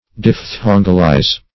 Search Result for " diphthongalize" : The Collaborative International Dictionary of English v.0.48: Diphthongalize \Diph*thon"gal*ize\ (?; 115), v. t. To make into a diphthong; to pronounce as a diphthong.
diphthongalize.mp3